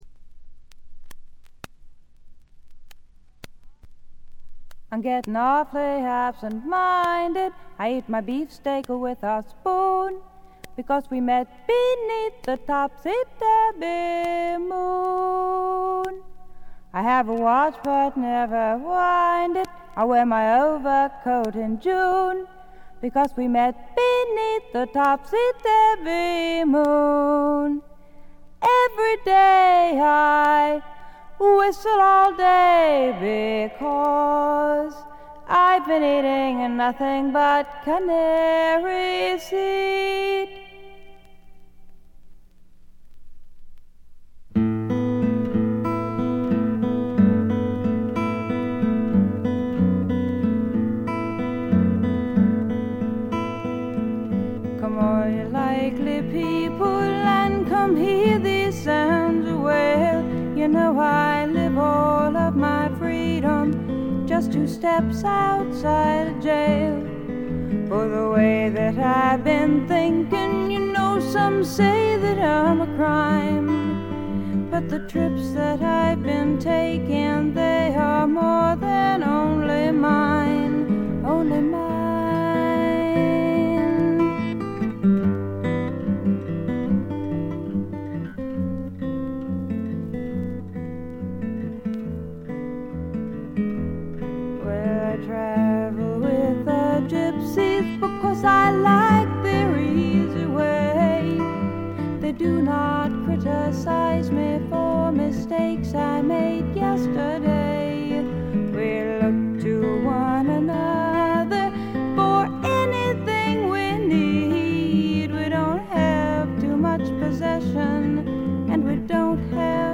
A1冒頭無音部から途中まで大きめの周回ノイズ。
自身のギターによる弾き語りで、このアコースティックギターの音が何とも素晴らしく心のひだに沁みわたっていきます。
全体を貫く清澄な空気感と翳りのあるダークな感覚がたまりません。
試聴曲は現品からの取り込み音源です。
Vocals, Guitar